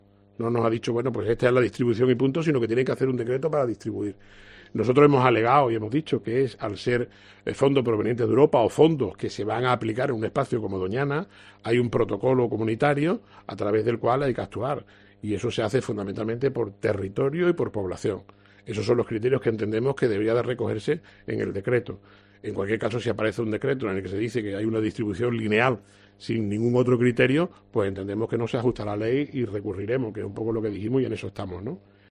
Paco Bella, alcalde de Almonte
Unas declaraciones que ha realizado en la presentación de una colección de reproducciones de fotografías antiguas de la romería del Rocío que capturan momentos simbólicos de su historia, “todos cargados de fervor, de alegría y de fuerza”.